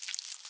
step2.ogg